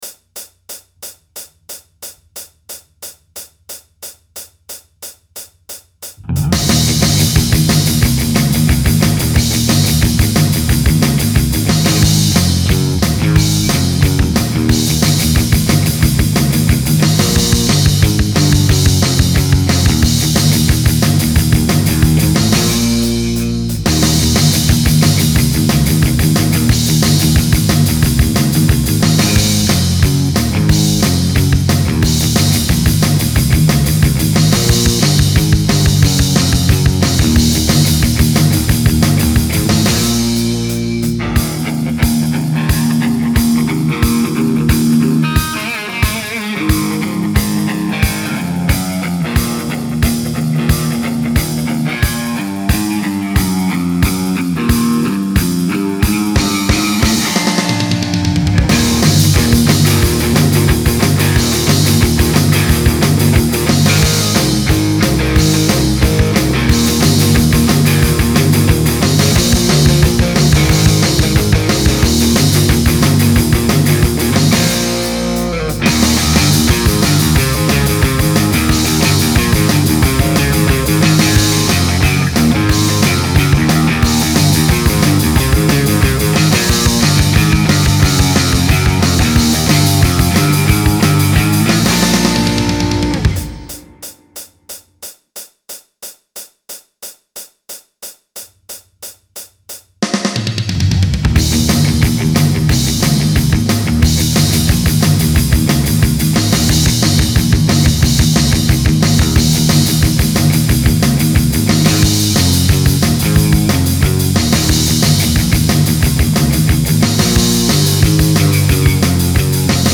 You be the main guitar part
*TIP:  The song begins with a 4 count on the hi-hat.